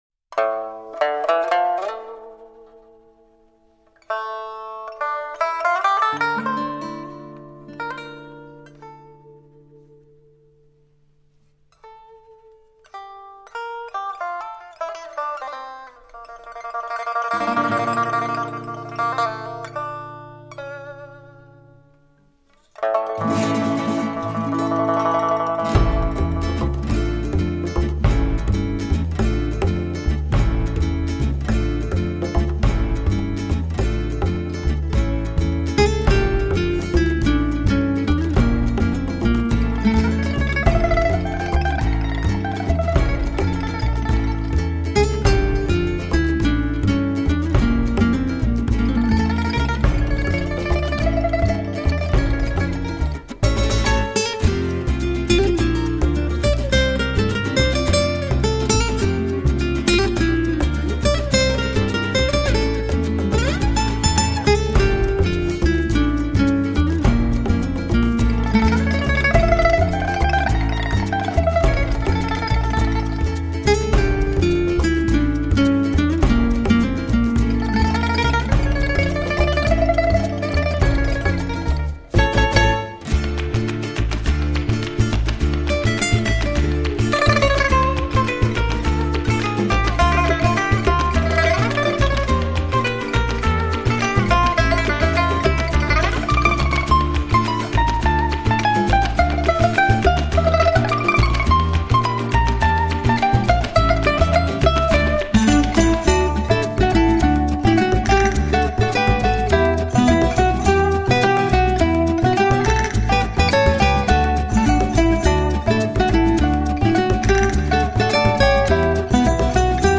琶乐和吉他结合,别有一种心情
琵琶扮演的吉普赛女孩好像更具野性，那一副张扬的嘴脸似乎更加生动和光彩照人。